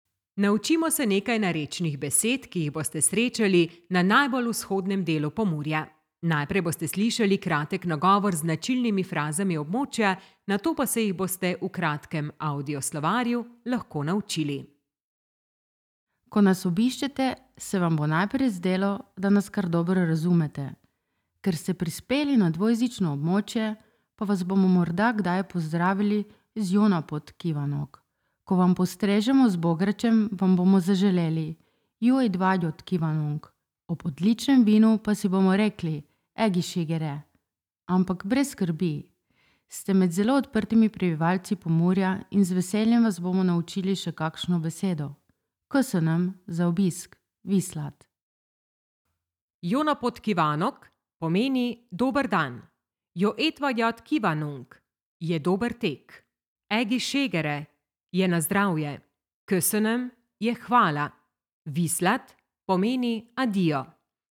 Egy tartomány, öt nyelvjárás.
lendvai nyelvjárás